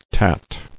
[tat]